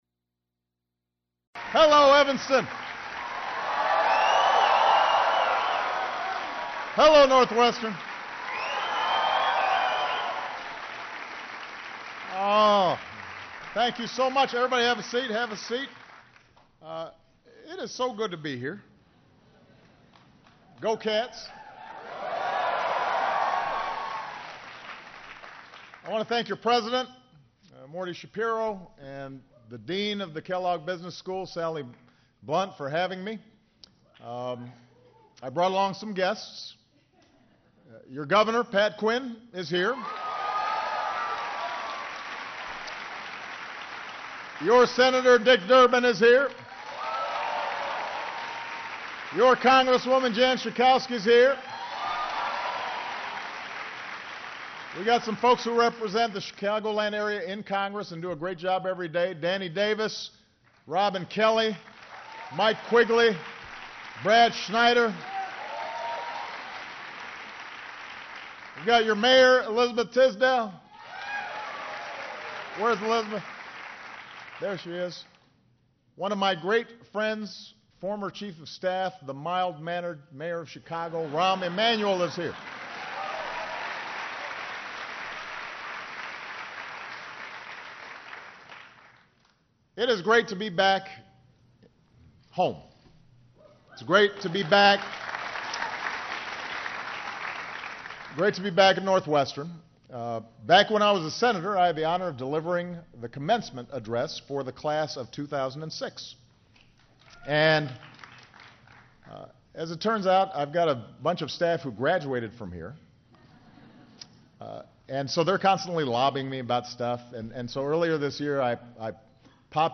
U.S. President Barack Obama speaks to young entrepreneurs at the Northwestern University Kellogg School of Management about the new foundation of America's 21st century economy
Obama says that a strong U.S. economy is vital to continuing in that role. He challenges his audience to be stewards of the country, pursue success and profit, and find ways to help other Americans be successful. Held in Evanston, Illinois.